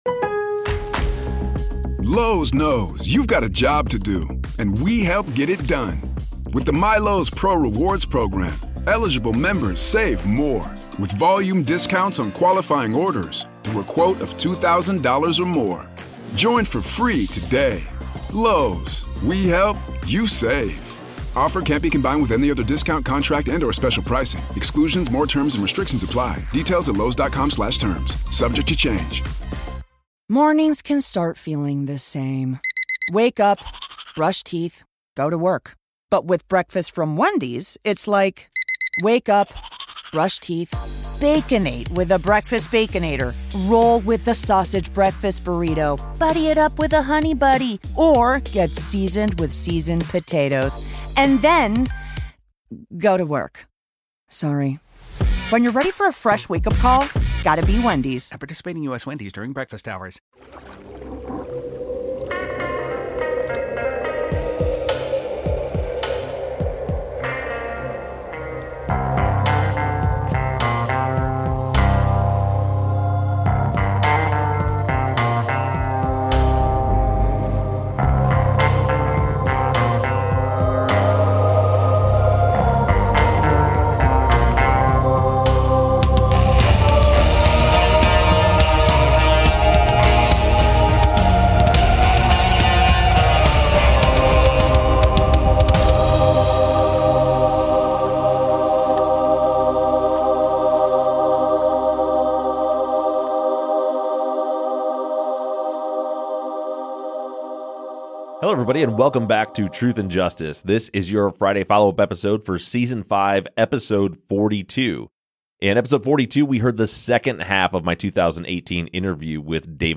True Crime, Documentary, Society & Culture